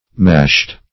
Mashed - definition of Mashed - synonyms, pronunciation, spelling from Free Dictionary